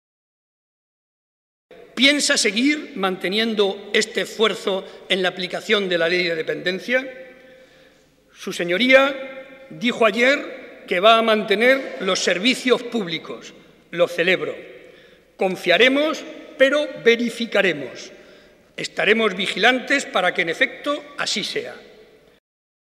Cortes de audio de la rueda de prensa
audio_Barreda_Discurso_Debate_Investidura_210611_3